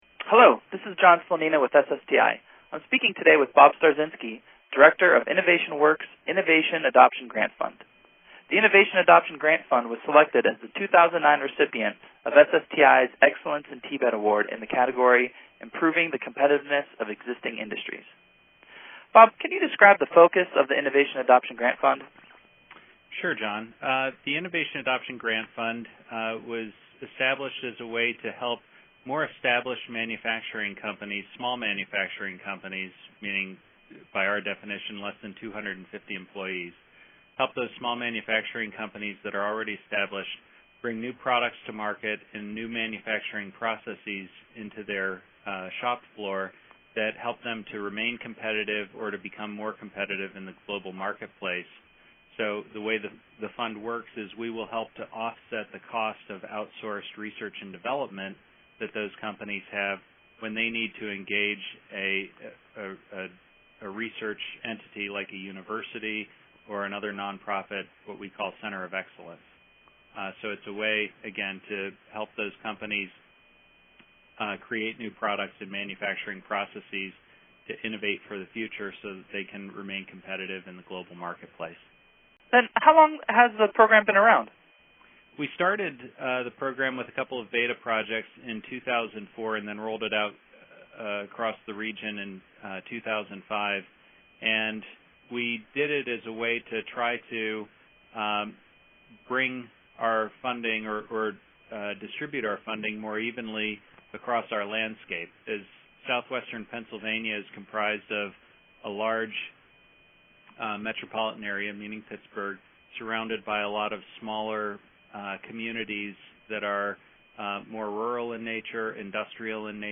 condensed version of the interview or the full conversation (17 minutes).